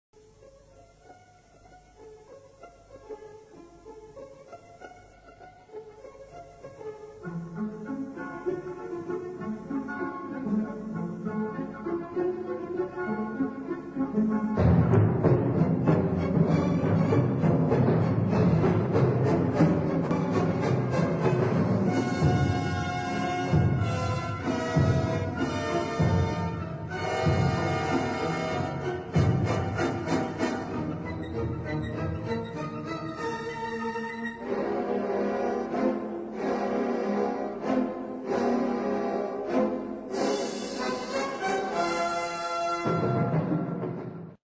Paukenkonzert
• Kammerensemble: 5 Pauken und Klavier